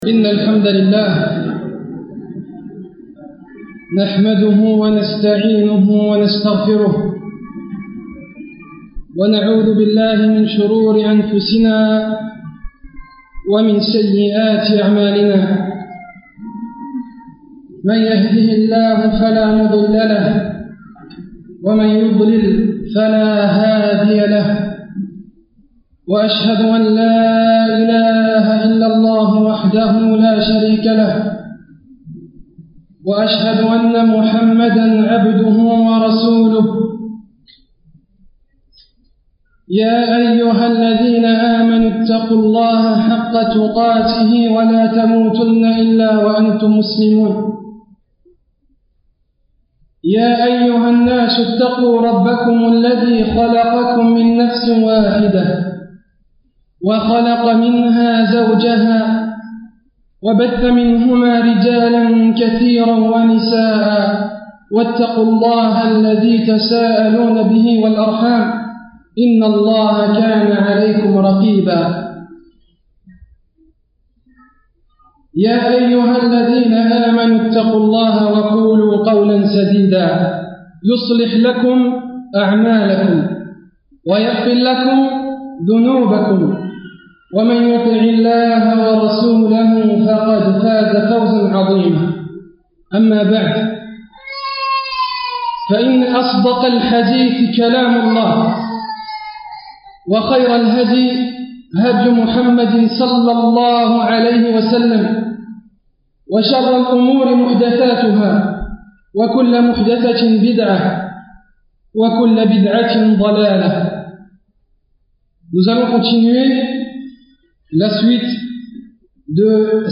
Cette série de conférences